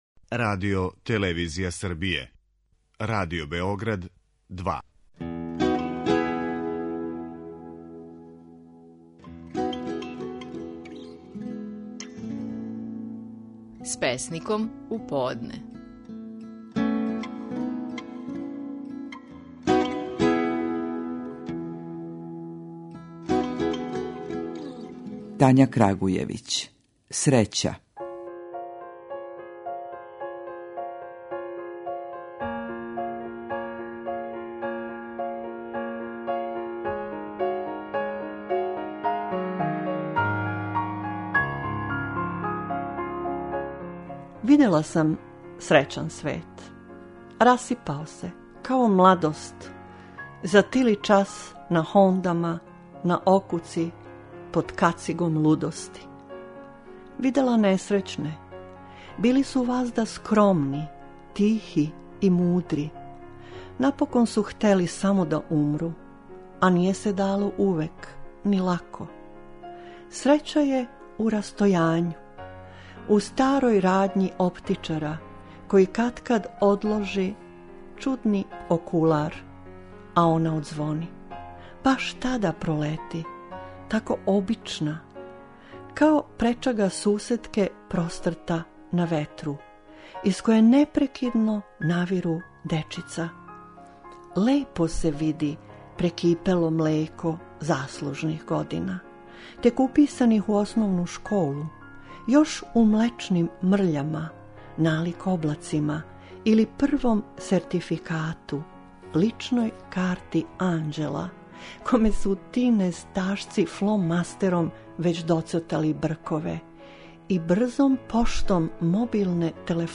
Наши најпознатији песници говоре своје стихове
Песникиња Тања Крагујевић говори своју песму "Срећа".